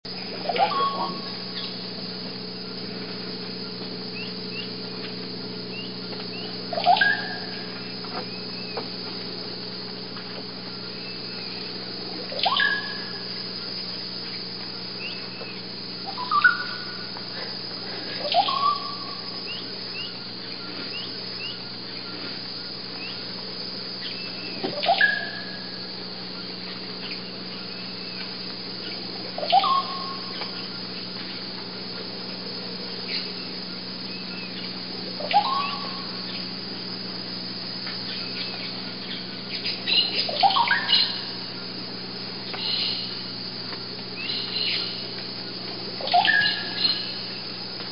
The oropendola is often heard before it is seen, with a burbling complex musical song.
Click to listen to the song of the russet-backed oropendola Listen to the song of the russet-backed oropendola Click to listen to the song of the russet-backed oropendola
(La Selva Lodge, Sucumbios/Napo, Ecuador)
All the while, he'll be singing his burbling liquid call.
rboropendola.mp3